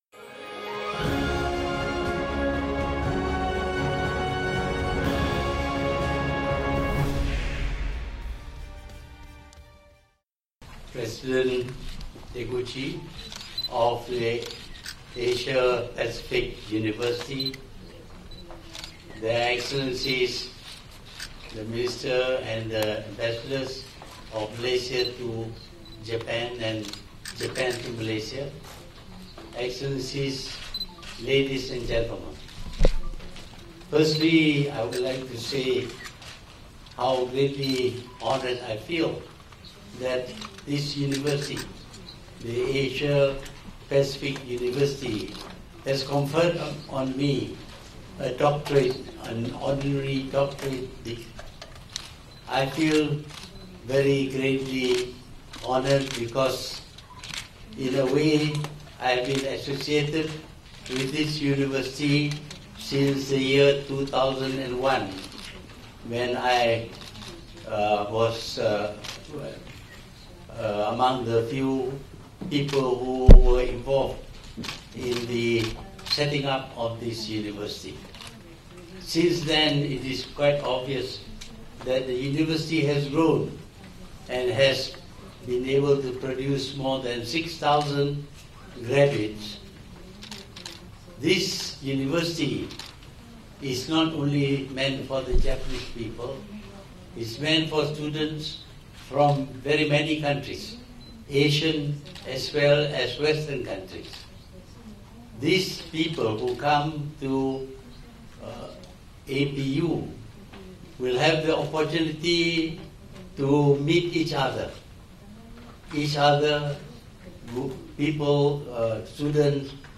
Ucapan Tun M di Universiti Asia Pacific Ritsumeikan, Jepun
Ikuti ucapan penuh Perdana Menteri Tun Dr Mahathir Mohamad ketika menerima Ijazah Doktor Kehormat dari Universiti Asia Pacific Ritsumeikan di Oita, Jepun.